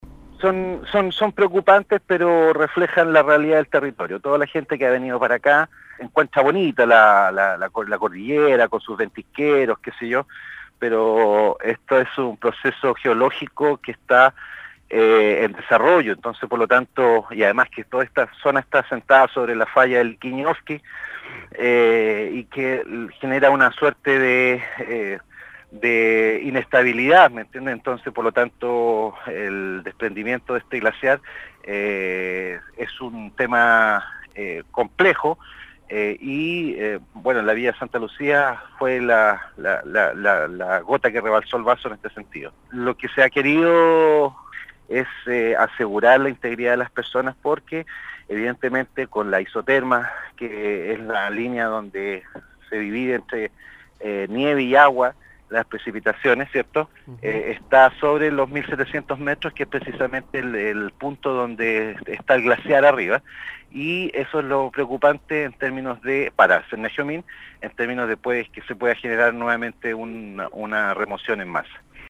El Gobernador de Palena Carlos Salas dijo que antes de pensar en una reconstrucción o relocalización de Villa Santa Lucía, se trabajará hasta ubicar a la última de las 8 personas que permanecen desaparecidas en la zona, tras el alud del sábado La autoridad provincial, en conversación con el programa «Primera Hora» de Radio Sago de Puerto Montt, reconoció que los pronósticos meteorológicos apuntan a un escenario riesgoso en la zona, con peligro real de nuevas remociones en masa.